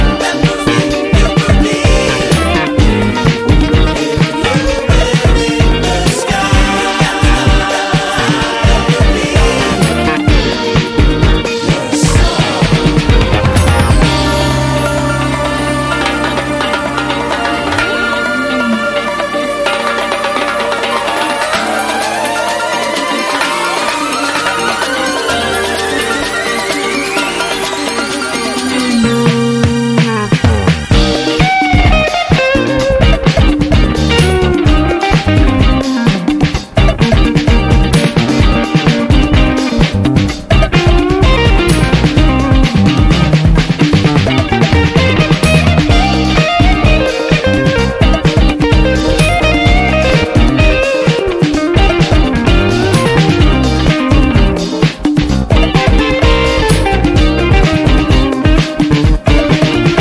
Disco Jazz Funk and Soul
A tour de force from start to finish.